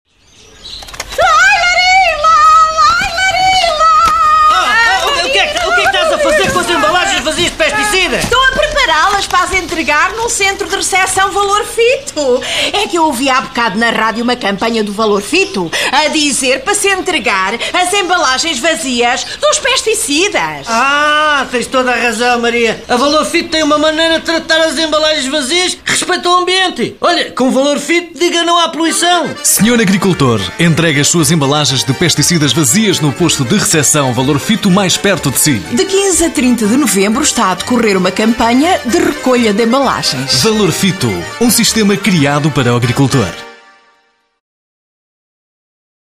Desde sempre, os spots publicitários elaborados pelos Parodiantes comportam um formato de comunicação com sentido humorístico!